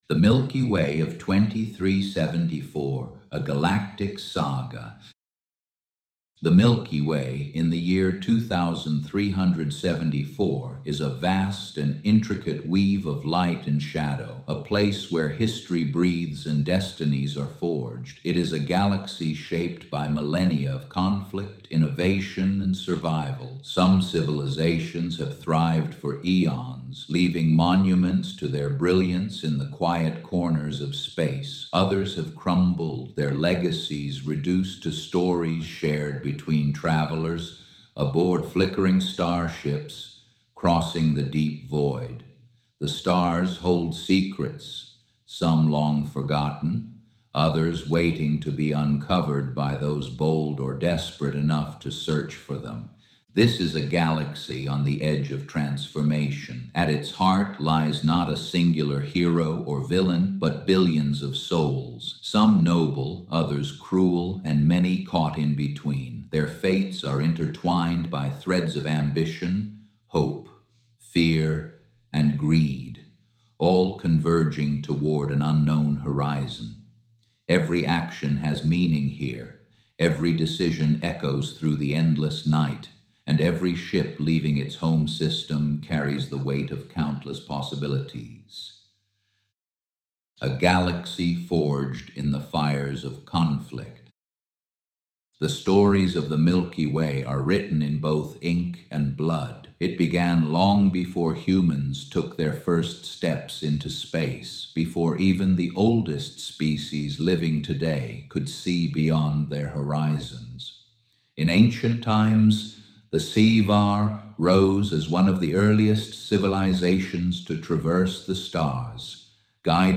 Narrated overview of the Legacy of the Horizon timeline used to introduce the IX. Timeline & Oral History section.